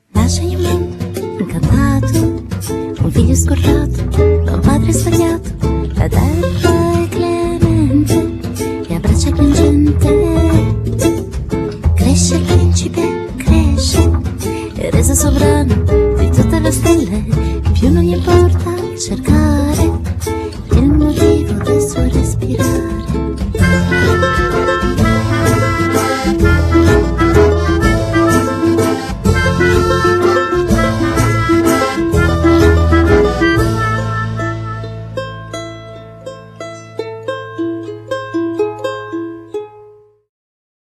śpiew / voz, akordeon / acordeón
gitara / guitarra, saksofon / saxo, charango, śpiew /coros
trąbka / trompeta
saksofon altowy / saxo alto
gitara elektryczna / guitarra eléctrica, mandolina, charango